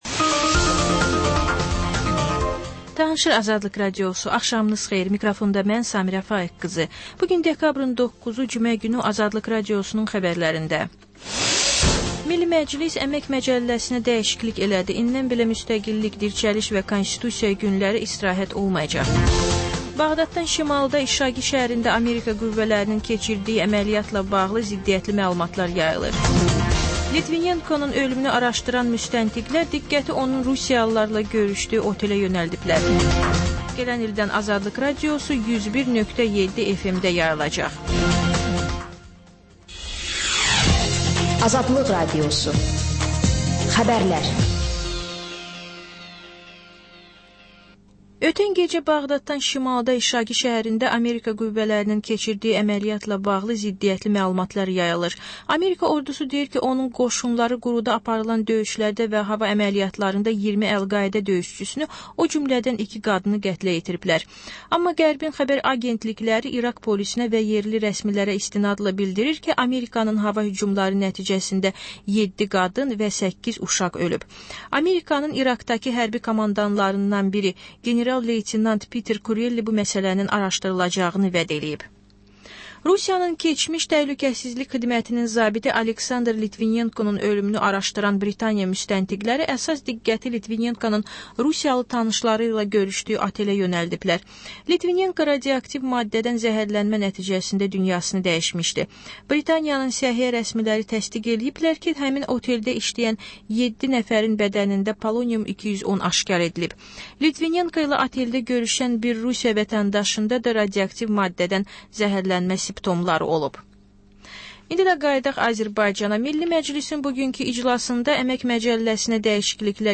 Xəbərlər, reportajlar, müsahibələr. Və sonda: Qlobus: Xaricdə yaşayan azərbaycanlılar barədə xüsusi veriliş.